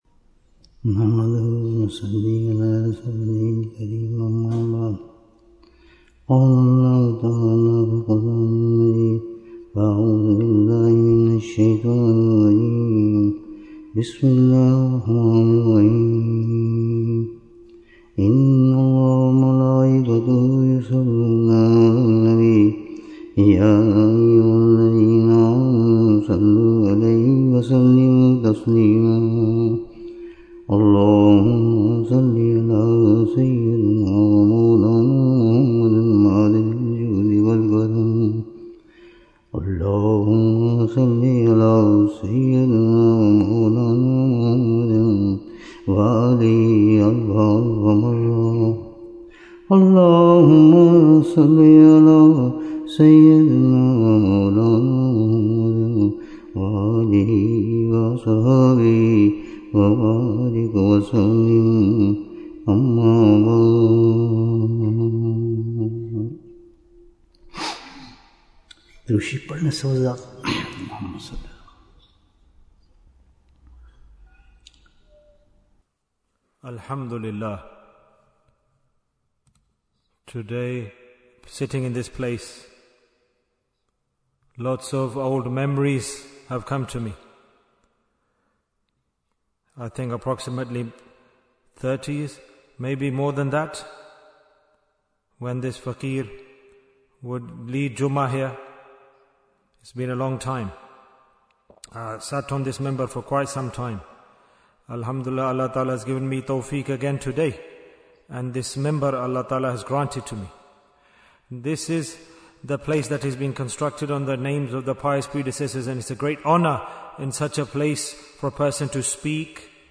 Jewels of Ramadhan 2025 - Episode 45 - The Last Asharah in Manchester Bayan, 39 minutes28th March, 2025